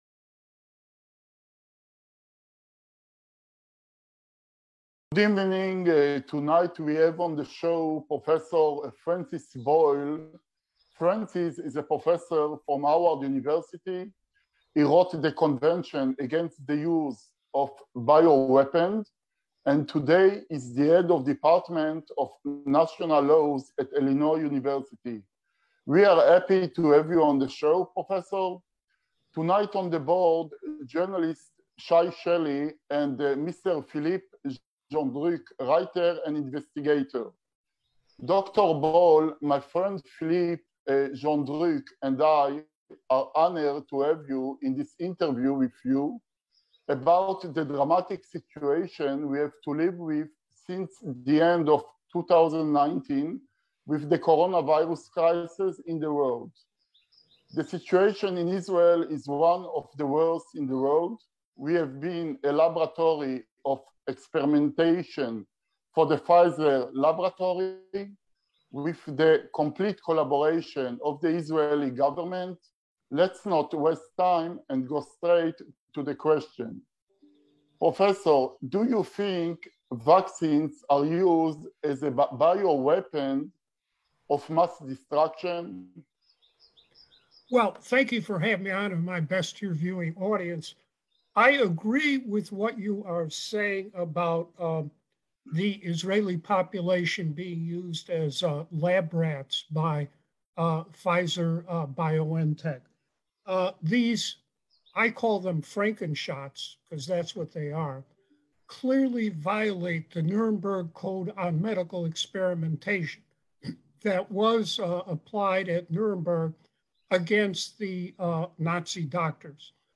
מראיין